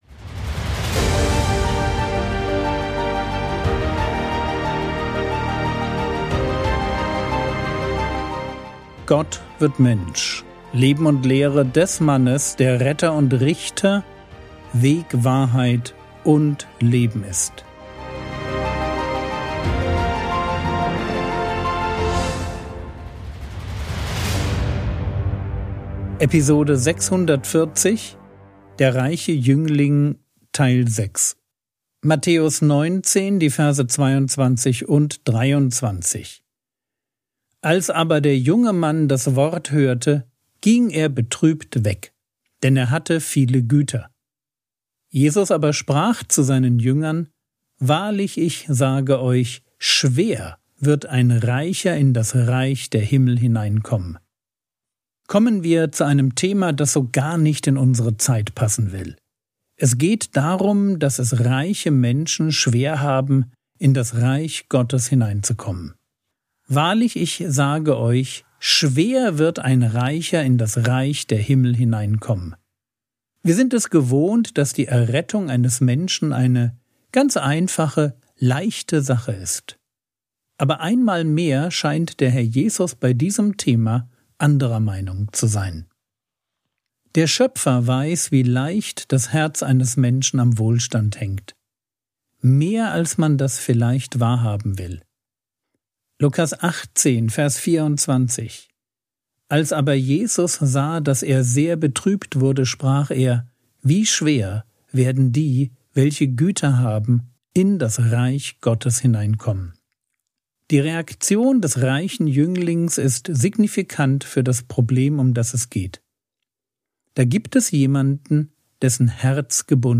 Episode 640 | Jesu Leben und Lehre ~ Frogwords Mini-Predigt Podcast